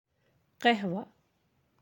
(qahwah)
How to say coffee in Arabic